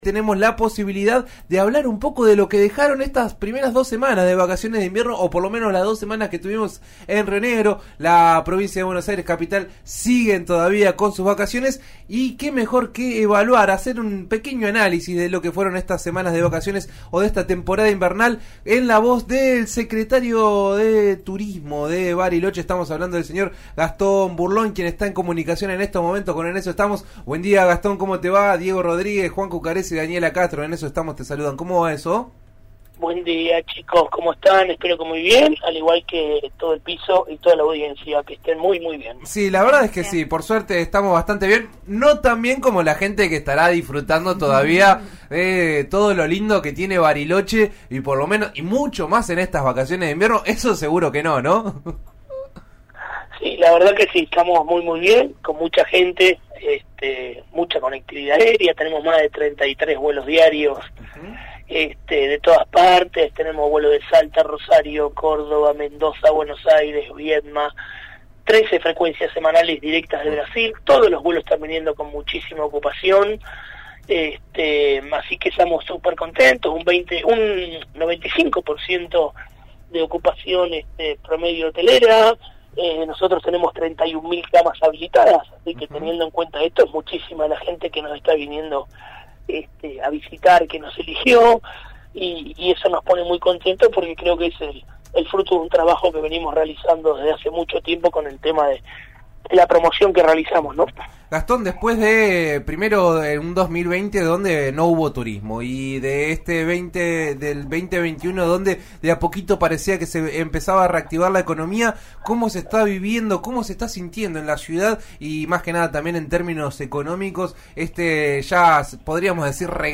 Gastón Burlón, Secretario de Turismo de Bariloche, habló con En Eso Estamos por RN RADIO y analizó los números positivos de las últimas semanas.
Eso nos pone muy contentos porque creemos que es el fruto de un trabajo que venimos haciendo», expresó Burlón durante el inicio de la conversación telefónica.